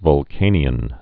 (vŭl-kānē-ən)